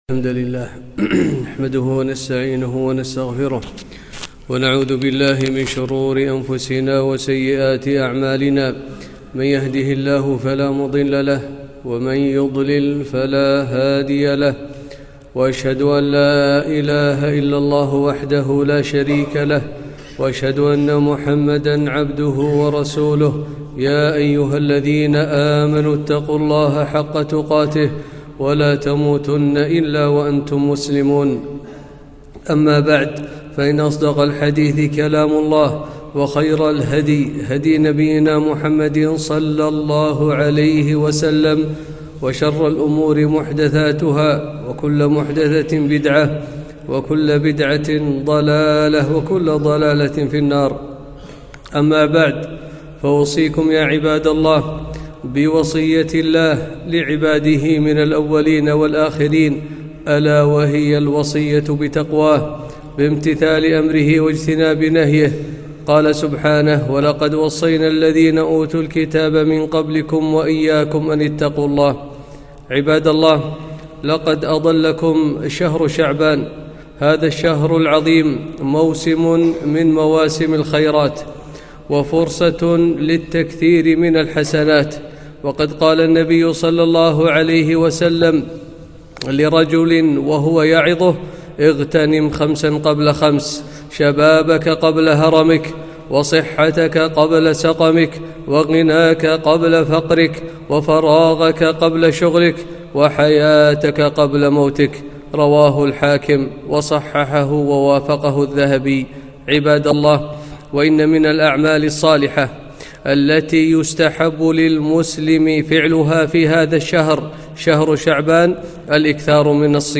خطبة - شعبان شهر القراء